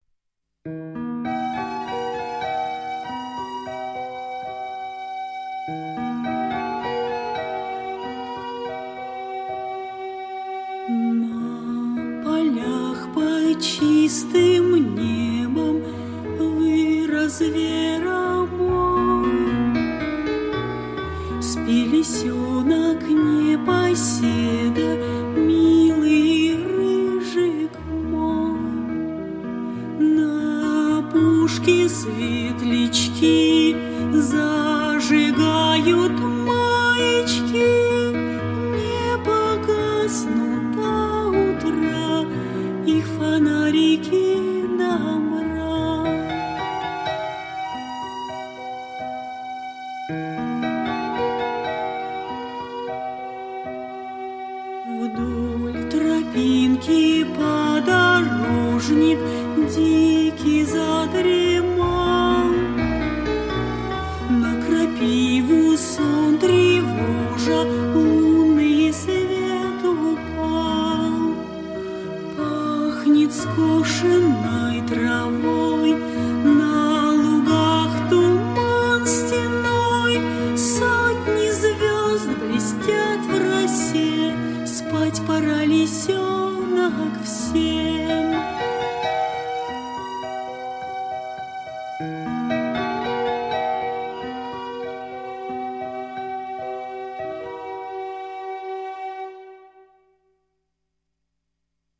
Детская песня
Детские песни